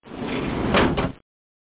drwclose.mp3